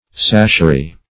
Search Result for " sashery" : The Collaborative International Dictionary of English v.0.48: Sashery \Sash"er*y\, n. [From 1st Sash .]